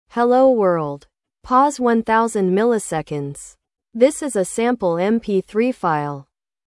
Audio glitches during speech pauses The tested MP3 file contains only spoken voice, with no background music.
Hello_world2.wav